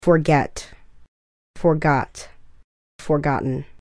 Рядом я подготовила произношение и перевод.